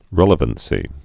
(rĕlə-vən-sē)